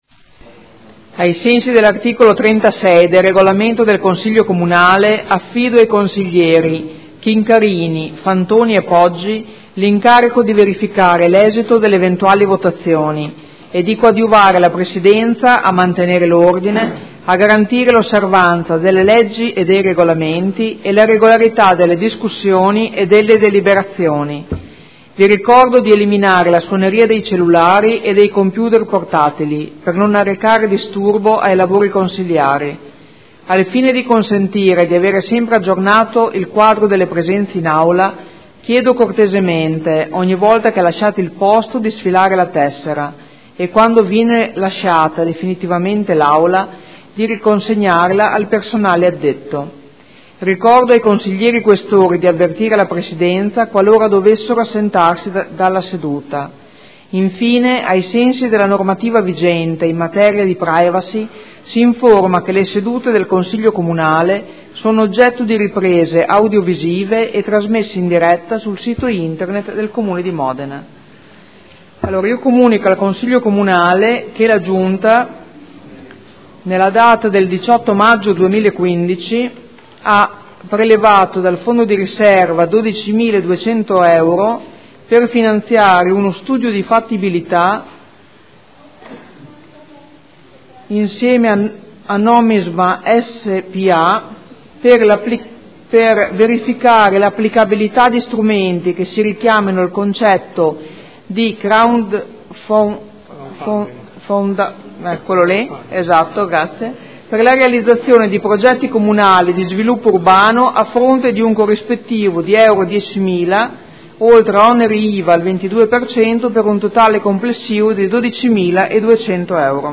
Seduta del 28/05/2015.